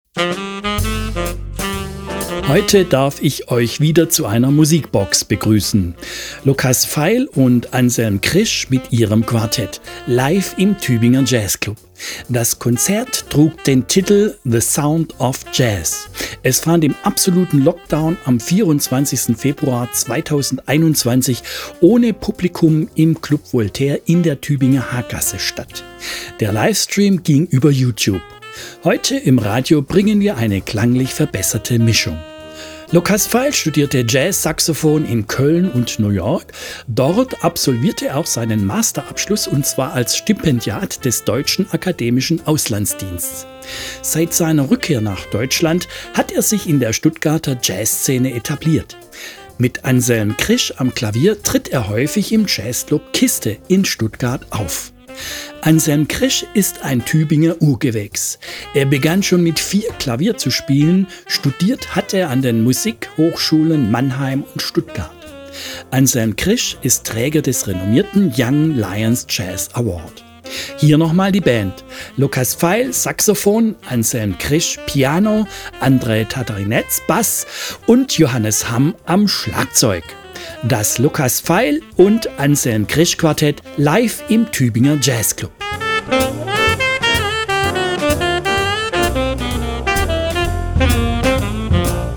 Geschmackvolle Arrangements
swingender, groovender Mainstream-Jazz
sax
piano
bass
drums